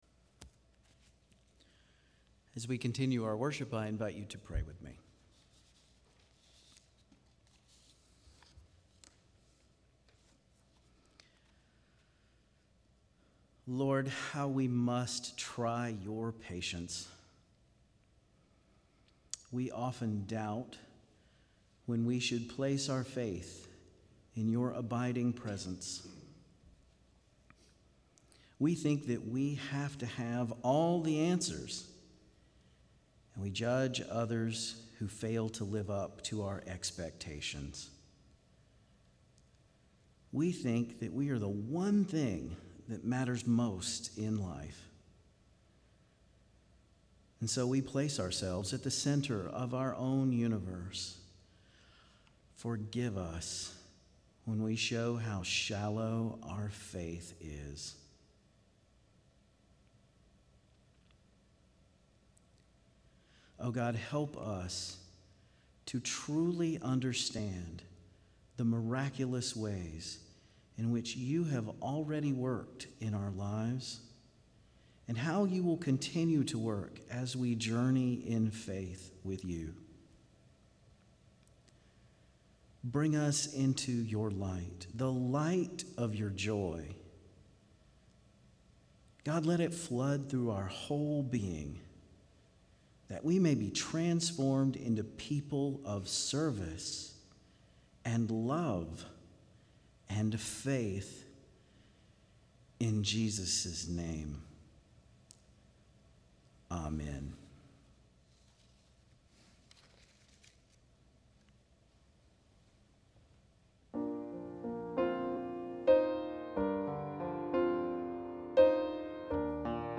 Bible Text: Isaiah 62:1-5 | Preacher